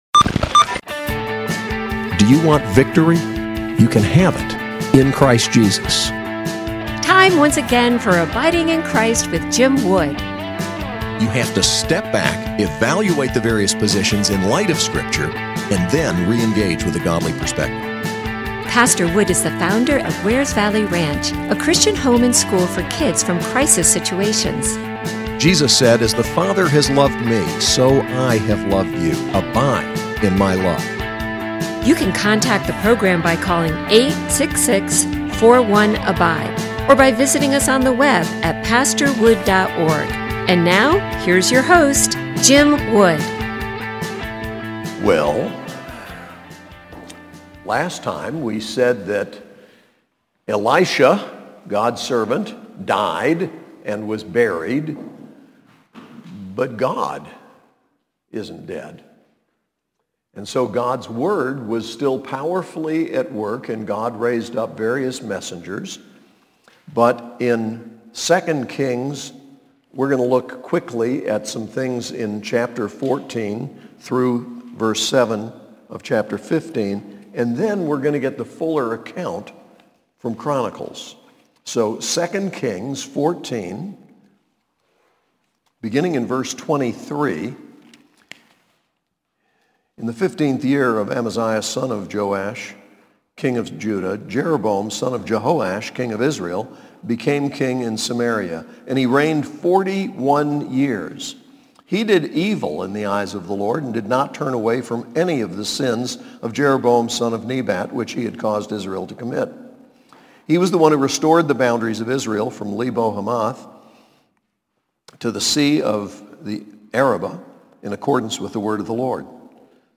SAS Chapel: 2 Kings 14:23-15:7 and 2 Chronicles 26